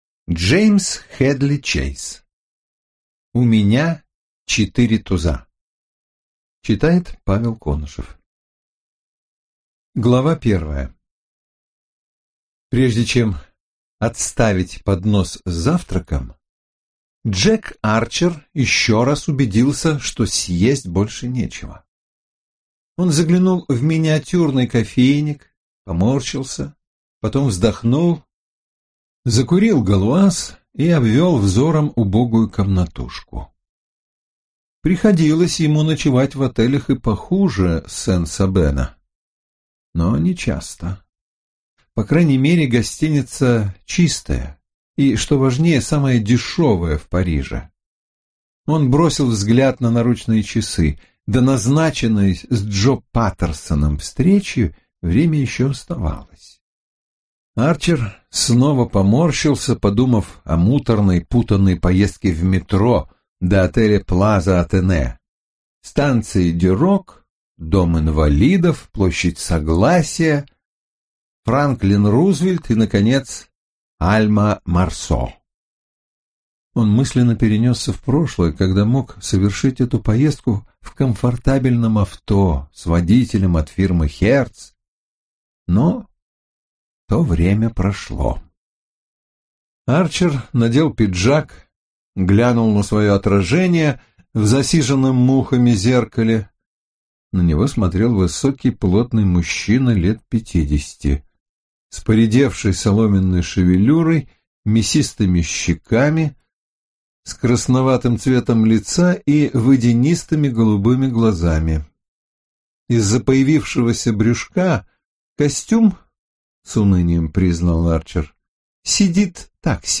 Аудиокнига У меня четыре туза | Библиотека аудиокниг